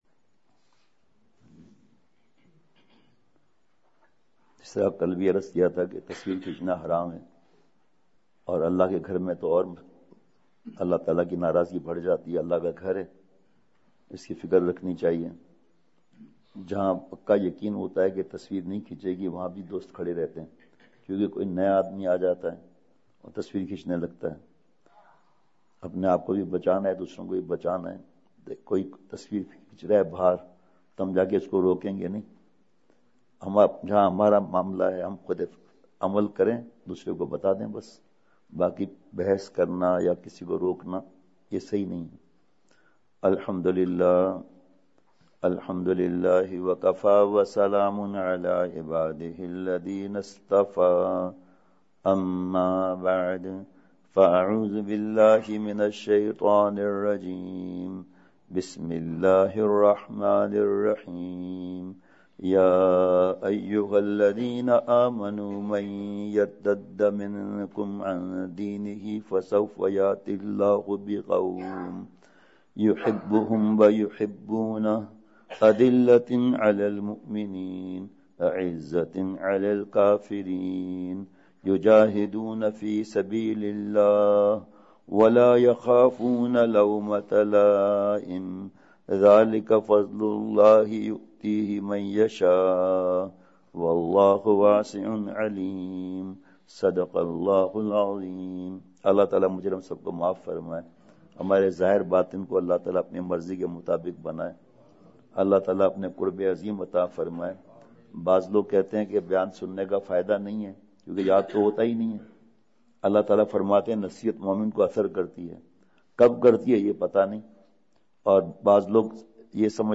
اصلاحی مجلس کی جھلکیاں *بمقام:*ابوبکر مسجد سول لائن خانیوال